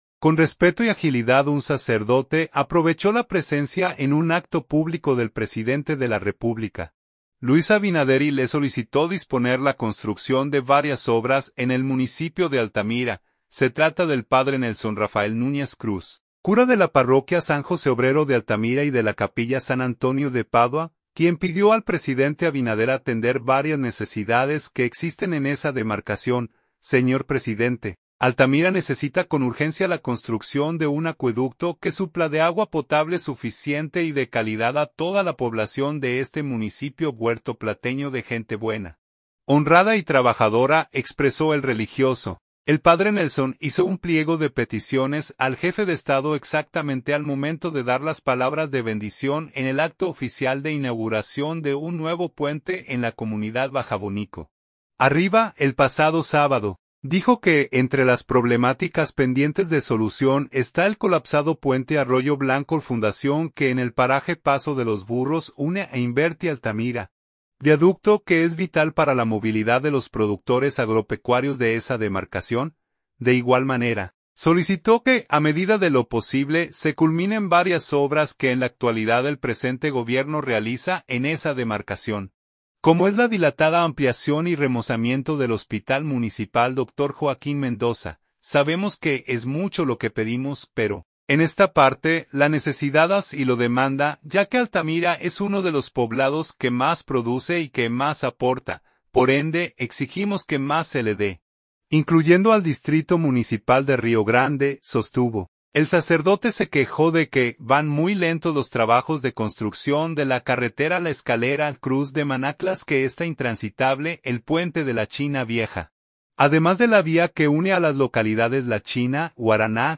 PUERTO PLATA.– Con respeto y agilidad un sacerdote aprovechó la presencia en un acto público del presidente de la República, Luis Abinader y le solicitó disponer la construcción de varias obras en el municipio de Altamira.